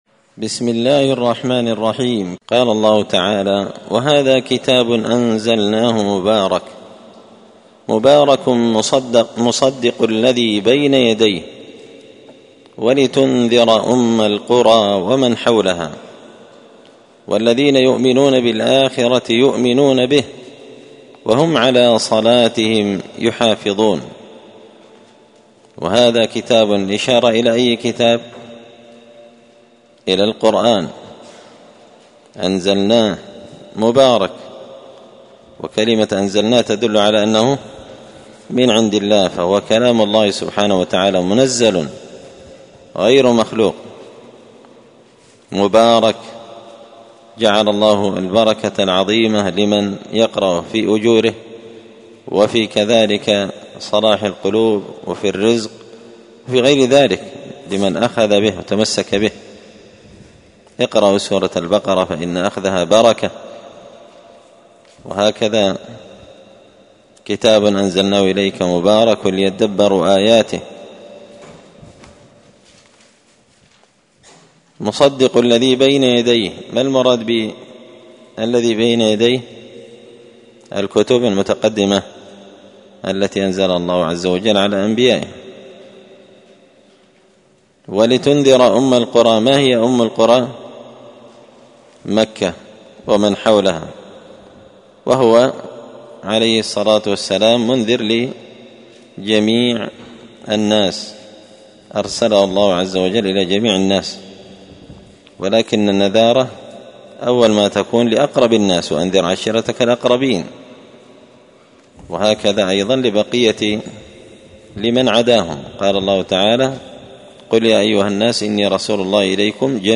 مختصر تفسير الإمام البغوي رحمه الله الدرس 330